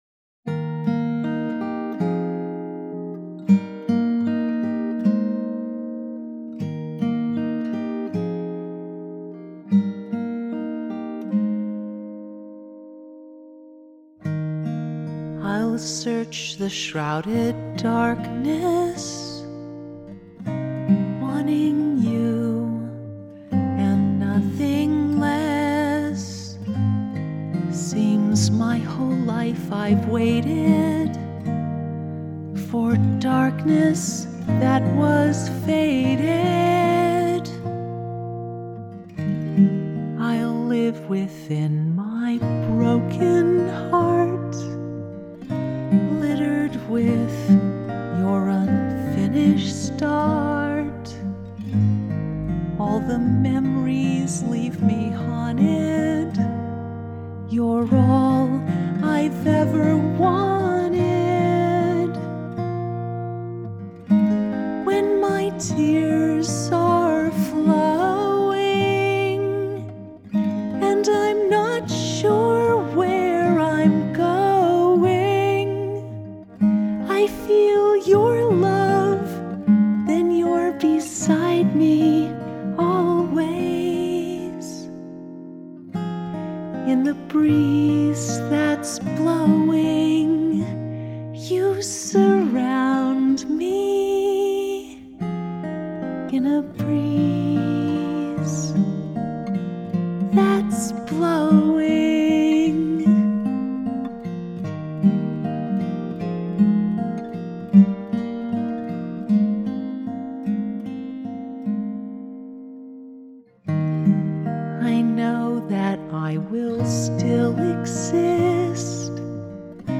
beside-me-always-acoustic-1-6-18.mp3